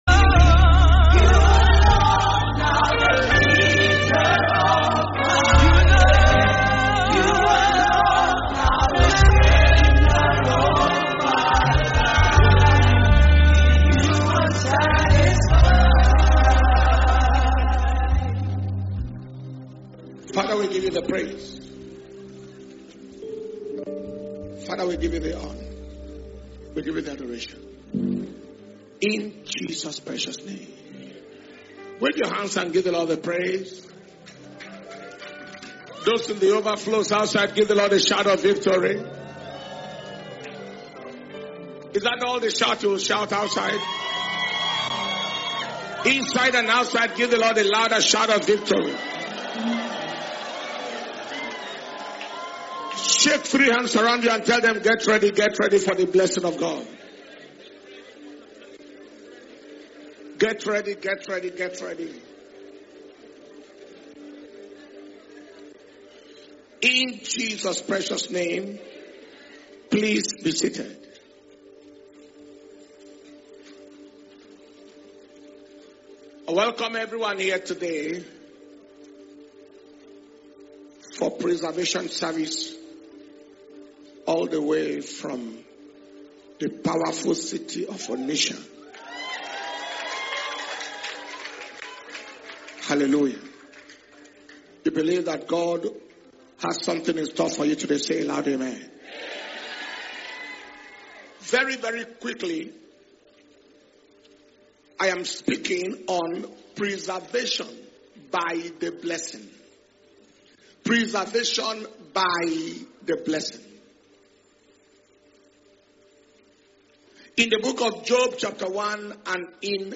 March 2026 Preservation And Power Communion Service Message